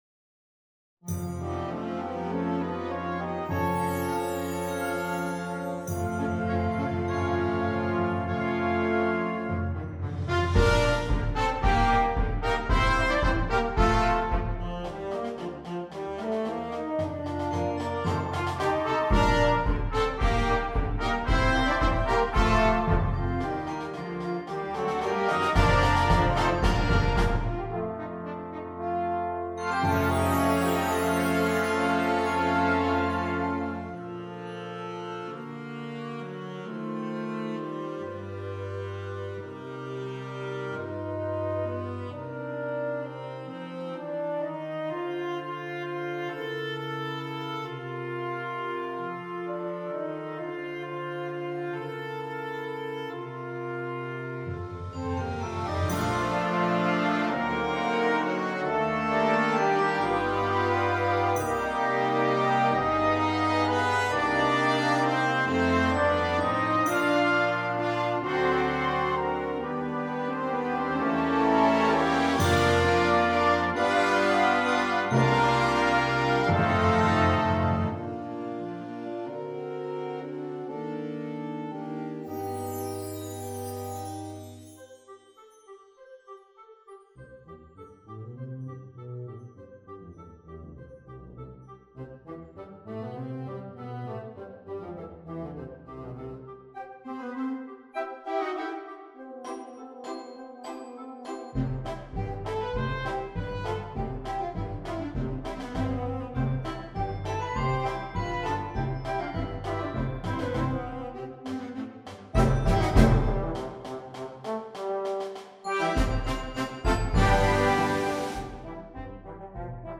Gattung: Für Flexible Besetzung
Besetzung: Blasorchester